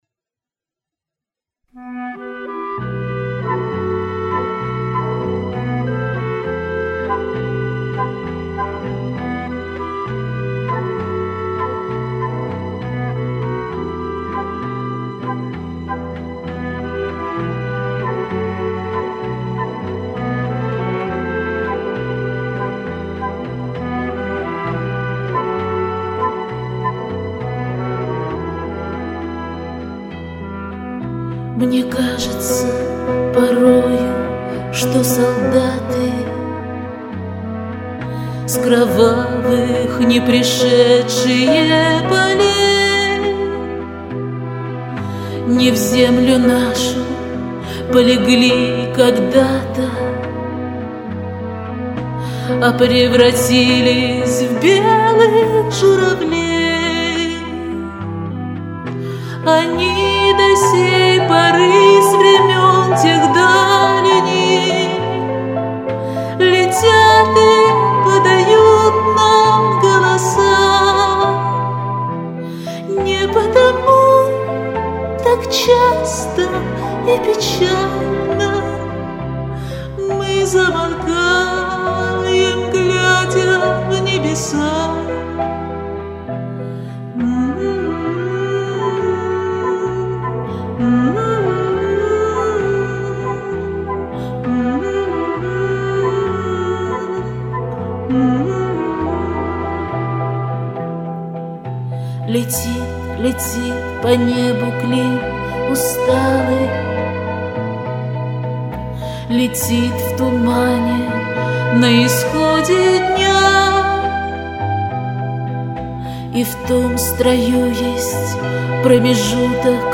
Чистила голос от шумов и  эквалайзер добавляла...
резковато может быть...